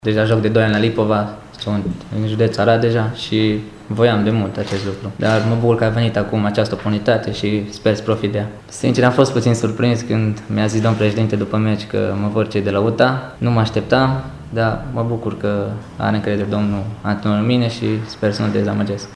la o conferința de presă organizată de club